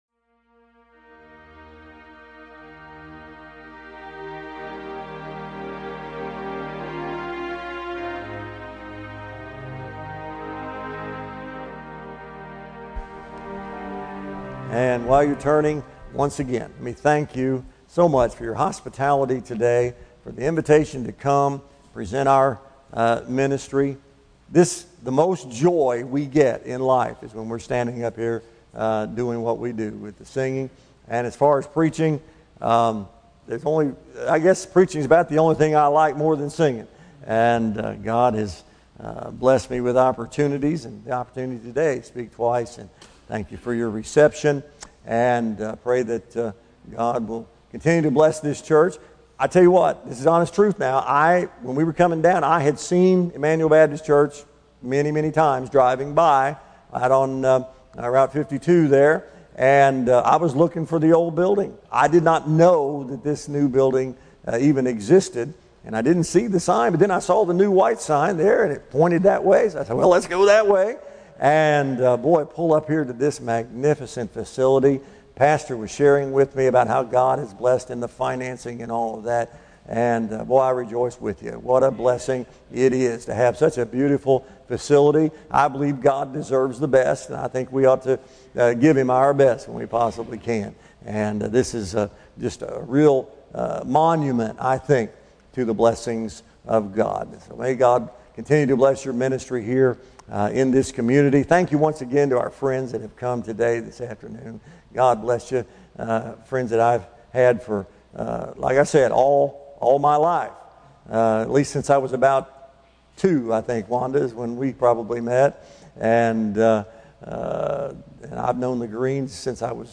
Job Service Type: Morning Service